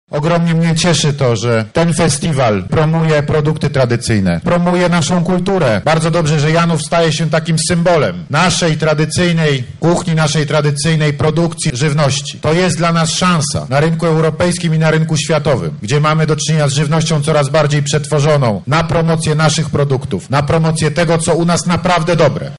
Duda w Janowie mówił o potrzebie promocji tradycyjnej, polskiej kultury.